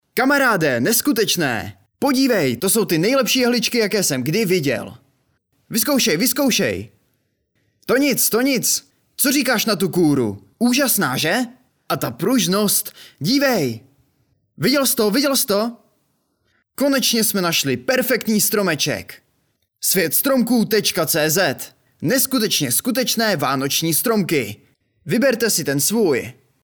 Hlas pro Vaše video! (voiceover)
K dispozici mám profesionální mikrofon a zvuk jsem schopen sám upravit a poté odeslat v nejvyšší možné kvalitě.
ukázka_.reklama_SvetStromku.mp3